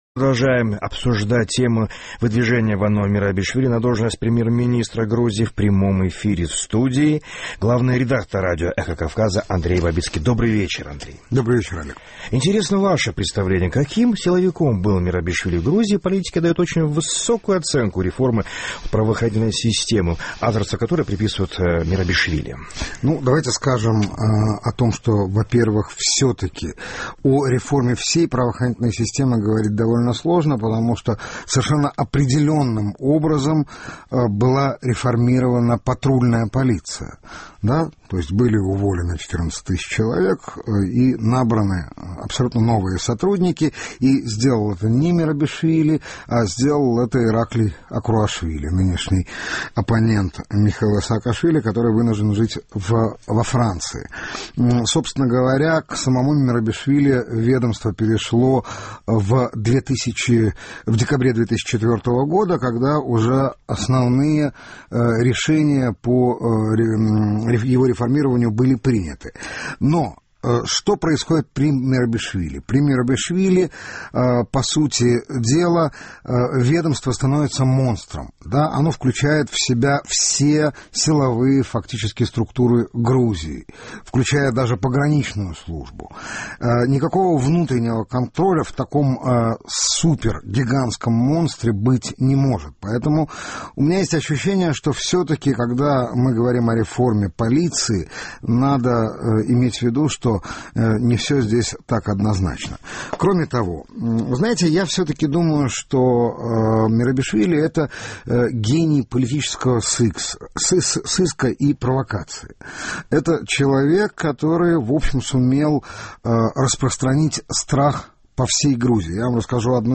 Мы продолжаем обсуждать тему выдвижения Вано Мерабишвили на должность премьер-министра. В студии главный редактор «Эхо Кавказа» Андрей Бабицкий.